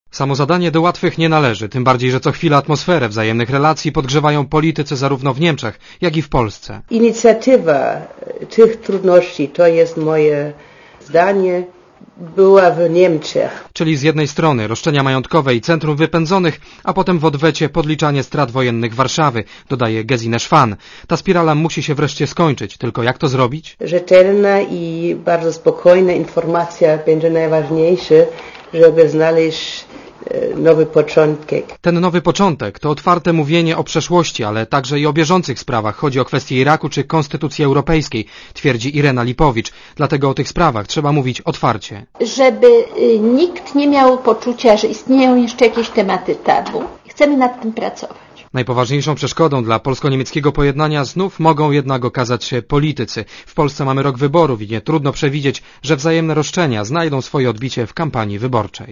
Źródło: Archiwum Relacja reportera Radia ZET Oceń jakość naszego artykułu: Twoja opinia pozwala nam tworzyć lepsze treści.